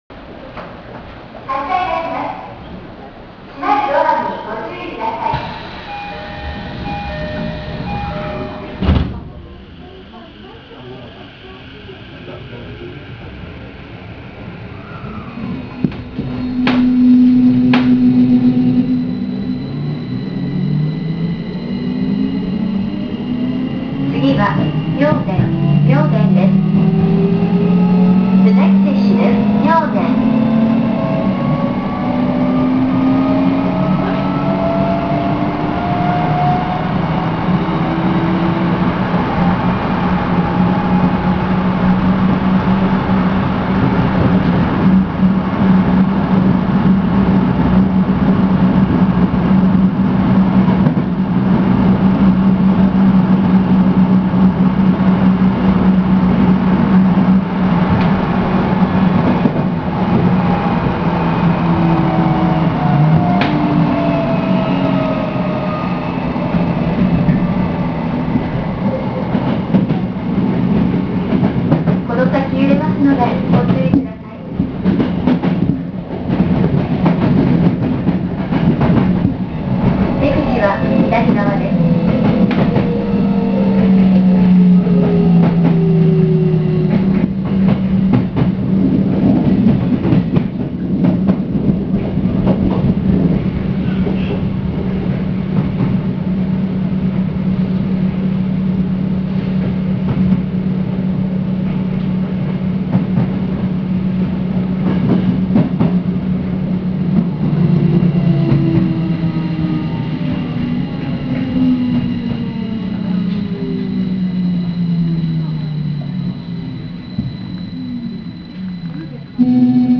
・05系（114〜118F：更新車）走行音
16000系や1000系に準じたDDMの走行音で、最初の低音の部分が随分響くような気がします。